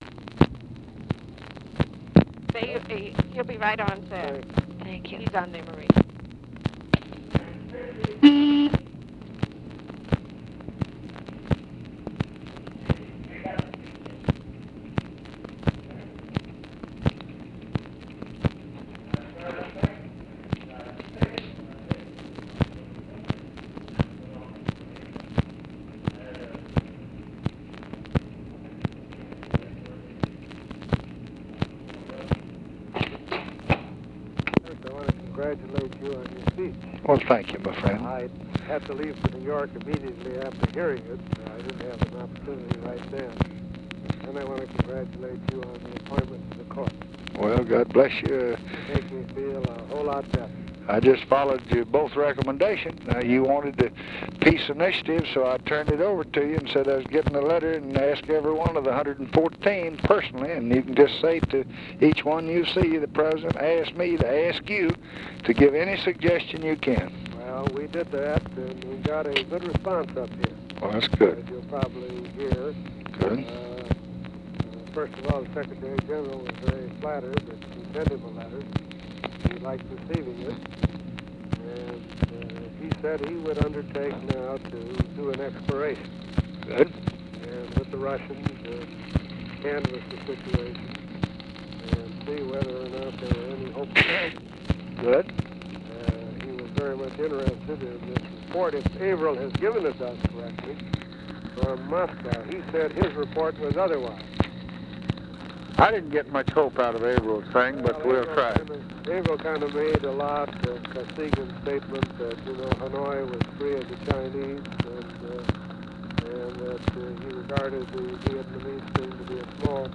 Conversation with ARTHUR GOLDBERG, OFFICE SECRETARY, TELEPHONE OPERATOR and OFFICE CONVERSATION, July 28, 1965
Secret White House Tapes